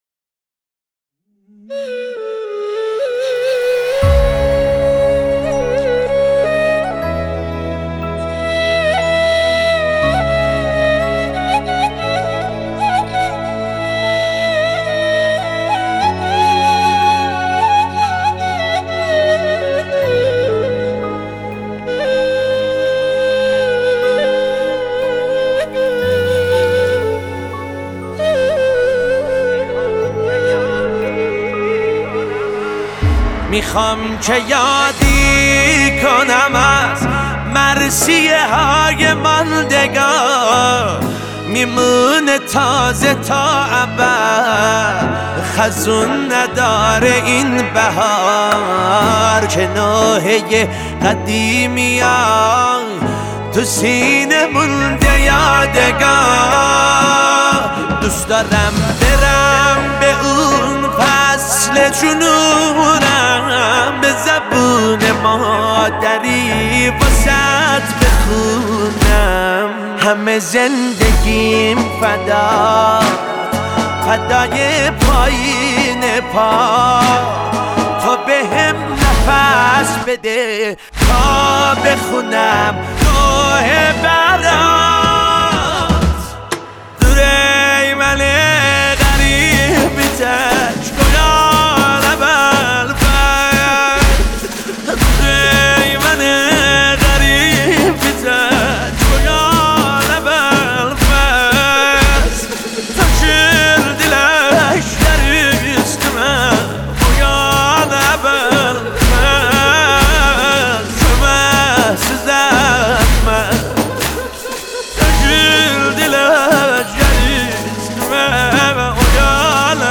مداحی استدیویی